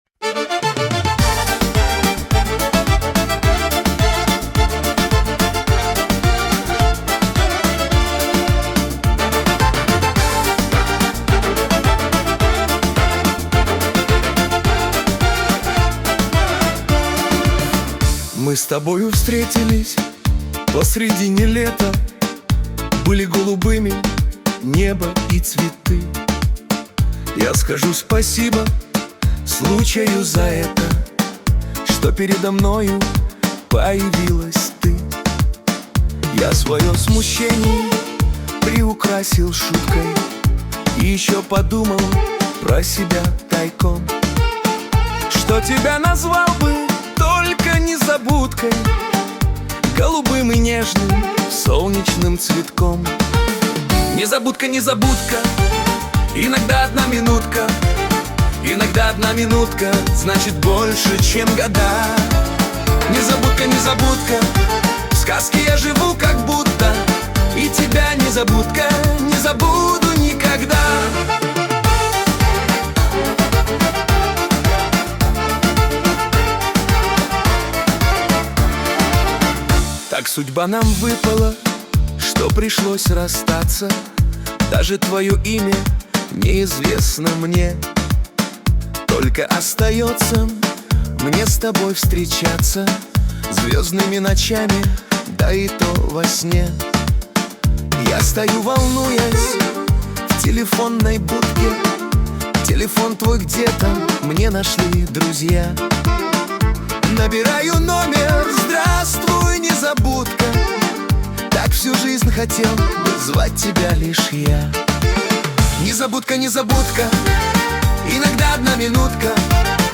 Песня передаёт чувство светлой грусти и надежды на встречу.